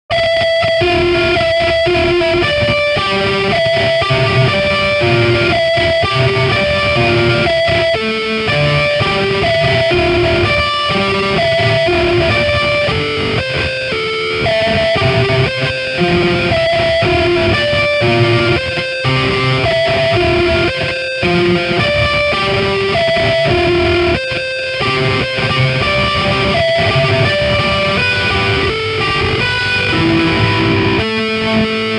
汉姆吉他
描述：合成器上的电吉他
Tag: 120 bpm Rock Loops Guitar Electric Loops 5.38 MB wav Key : Unknown